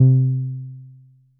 BASS1 C3.wav